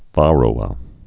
(värō-ə)